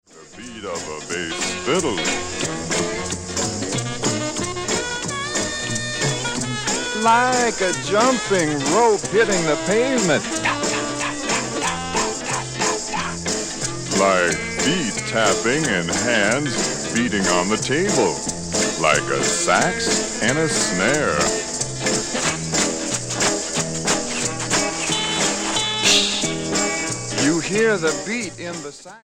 STYLE: Jesus Music
baritone speaking voice
fuzz guitars, groovy sounds and a sprinkling of avant garde